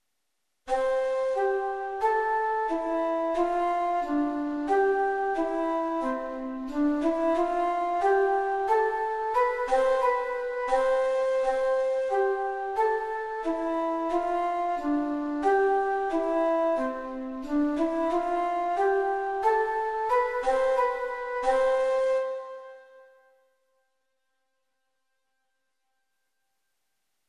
Canon voor 2 stemmen